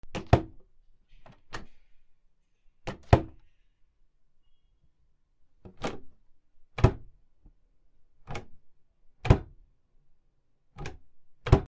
シンクの扉
『バタン』